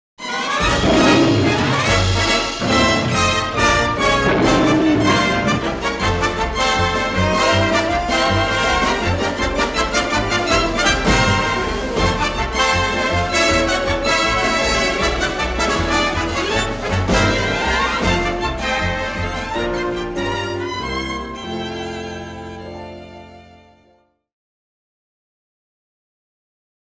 Музыкальное вступление